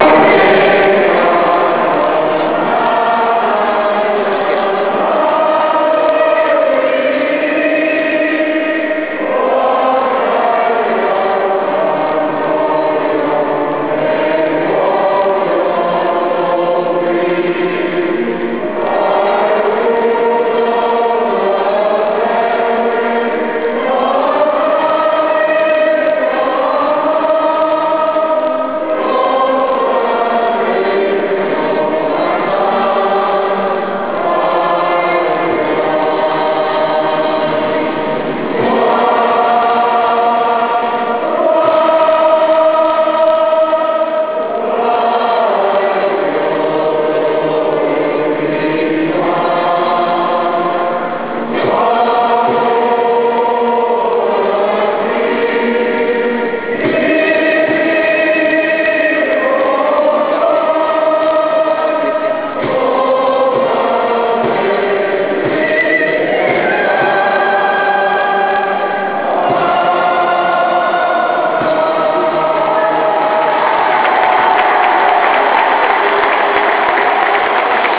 se non si carica scaricate da qui, l'ingresso dei reds nel Millenium!